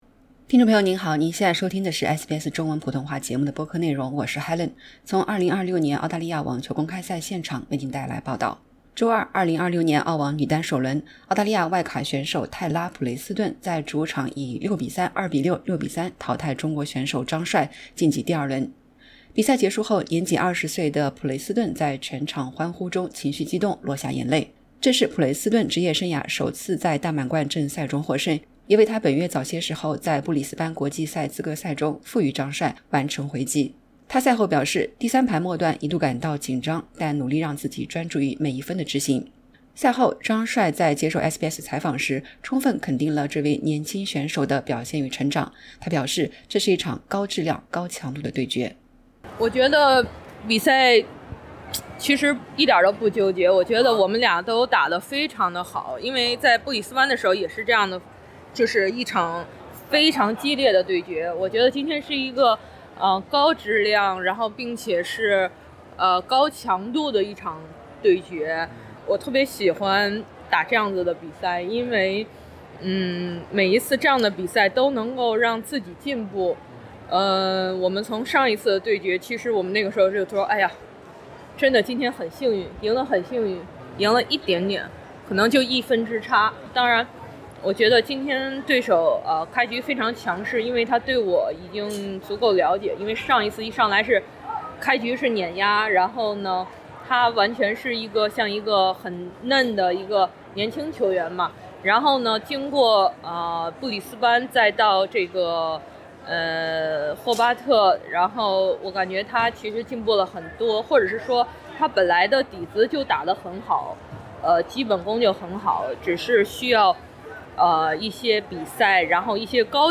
点击播放键收听完整采访 【澳网2026】首轮不敌20岁澳洲小将普雷斯顿 张帅：“为对手的进步高兴” SBS Chinese 06:59 Chinese 周二 （1 月 20 日），2026 年澳大利亚网球公开赛（AO）女单首轮，澳大利亚外卡选手泰拉·普雷斯顿（Taylah Preston）在主场以 6-3、2-6、6-3 淘汰中国选手张帅，晋级第二轮。